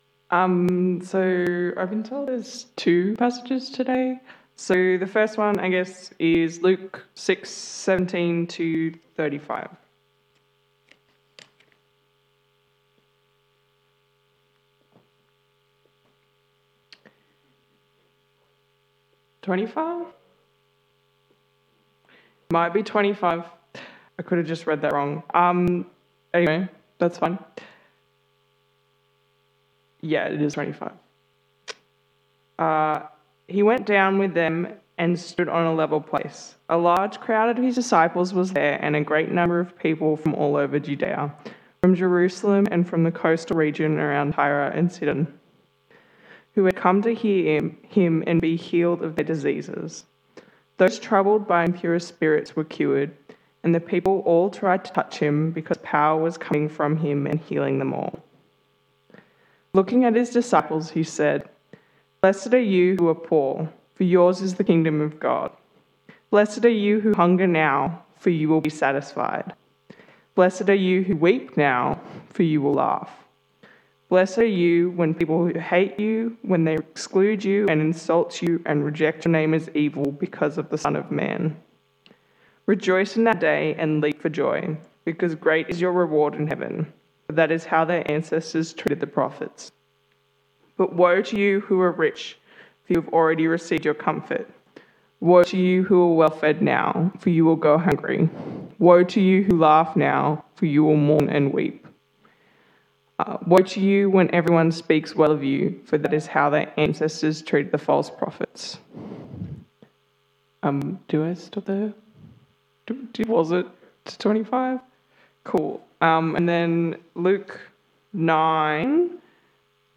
Bible Talk